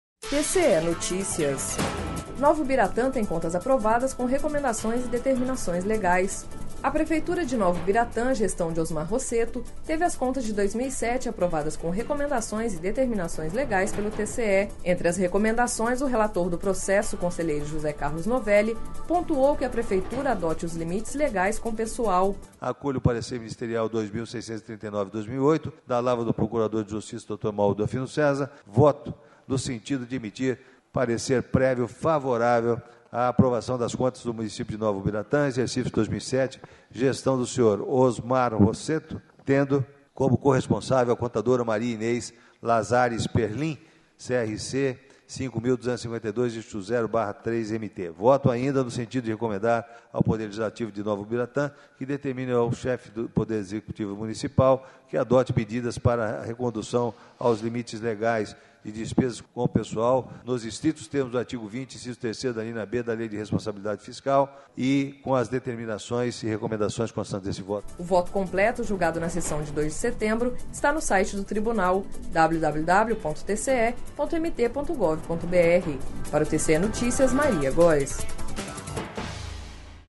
Sonora: José Carlos Novelli – conselheiro do TCE